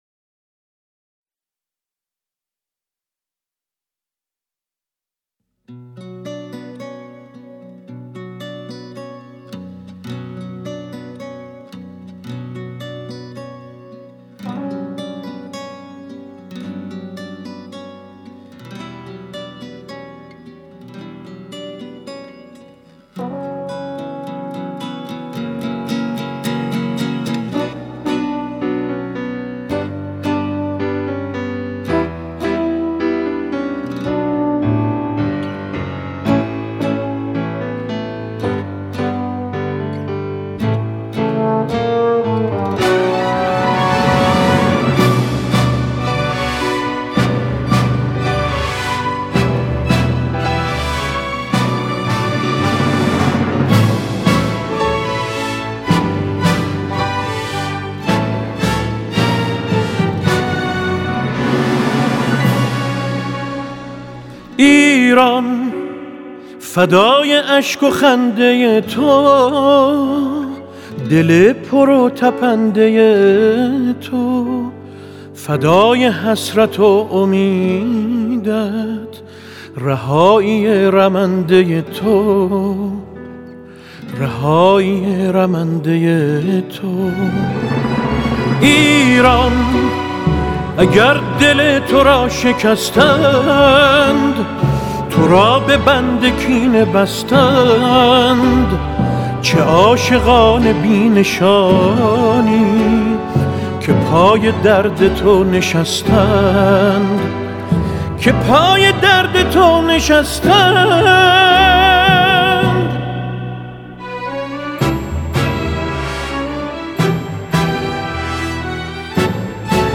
è un noto cantante di musica tradizionale persiana.